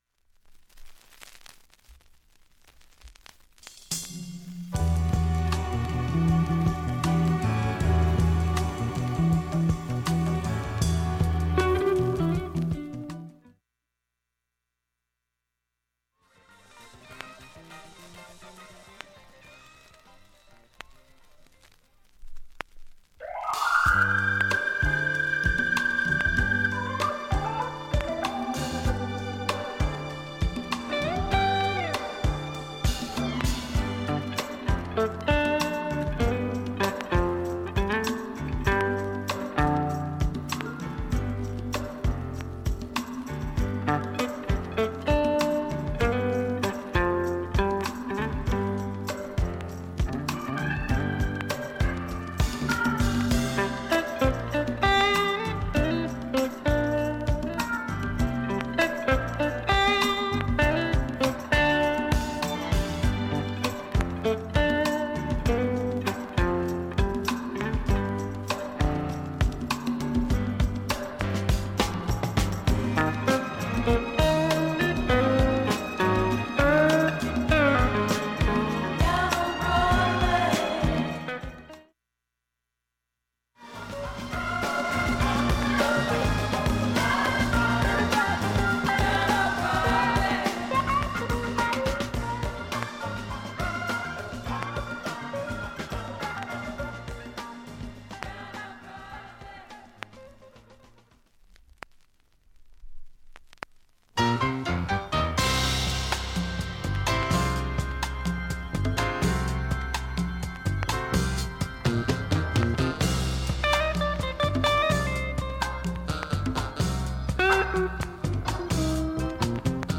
普通に聴けます音質良好全曲試聴済み。
A-1始る前にかすかにチリ出ます。
ほか３回までのかすかなプツが１箇所